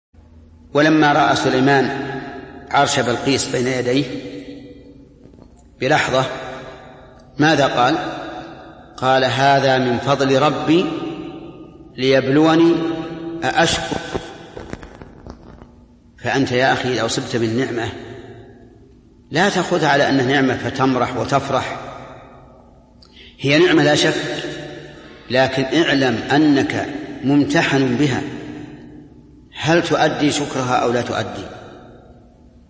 القسم: من مواعظ أهل العلم